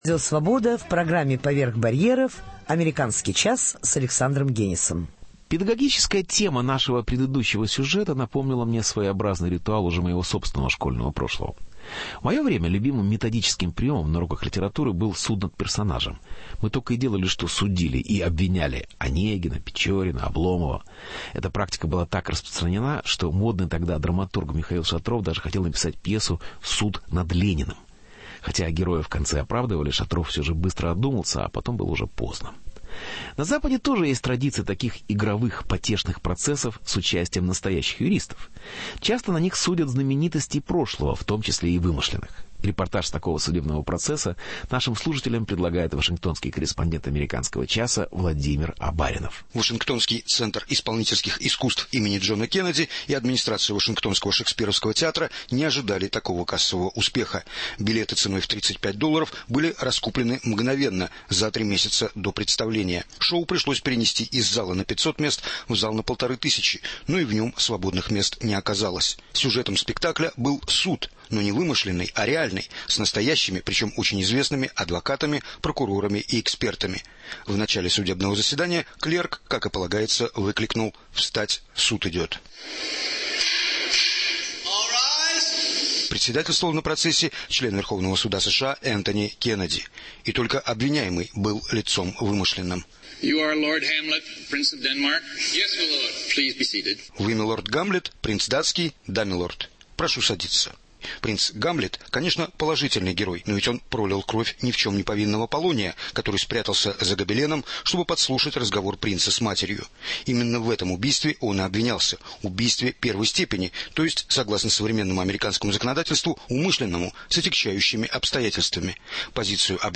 Суд над Гамлетом. Репортаж с настоящего процесса над вымышленным принцем